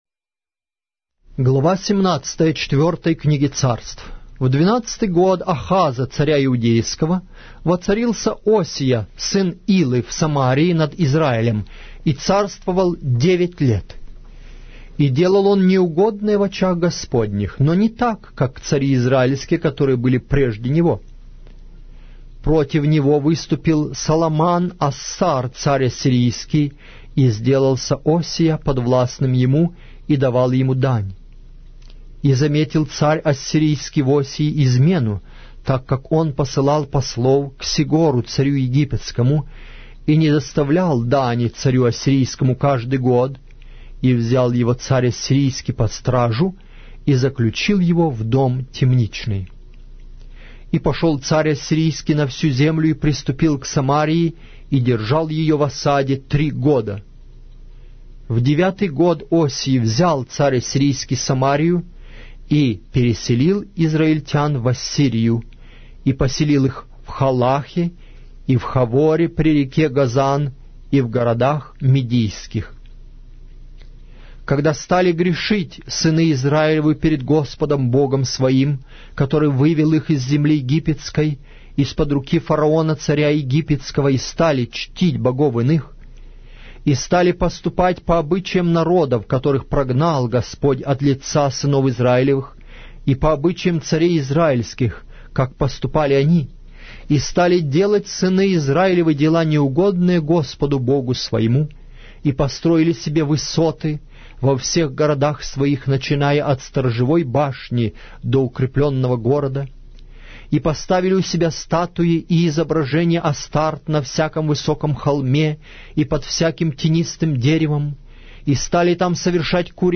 Аудиокнига: 4-я Книга Царств